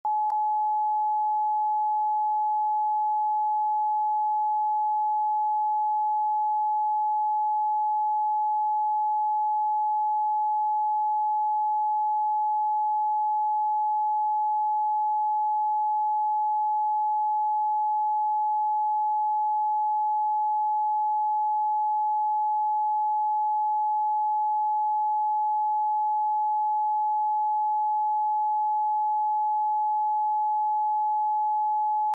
🧠🌪 12 seconds of 852Hz can turn the noise into stillness.